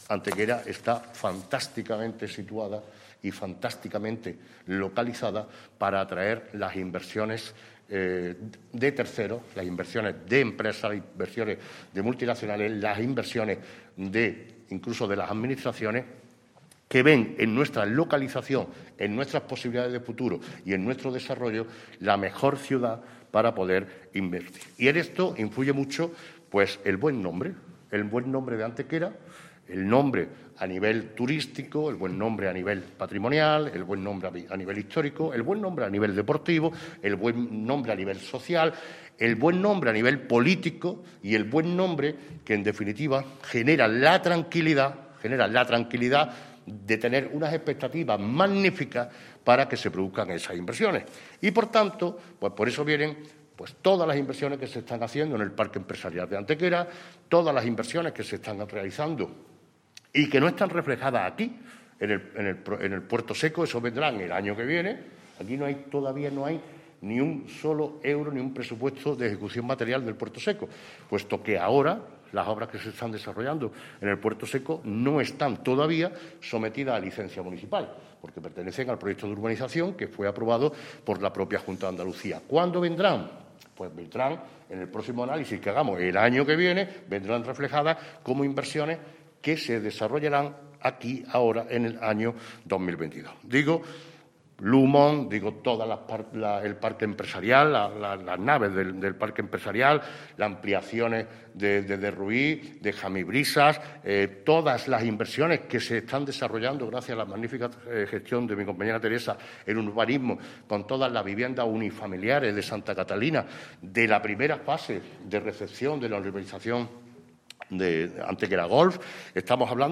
El alcalde de Antequera, Manolo Barón, y la teniente de alcalde delegada de Urbanismo, Teresa Molina, han informado en rueda de prensa del balance del número de expedientes de proyectos de licencias de obra –y su importe equivalente– presentados durante el pasado año 2021, ejercicio que supone ser el de la definitiva recuperación económica tras el negativo impacto de la pandemia del coronavirus en 2020.
Cortes de voz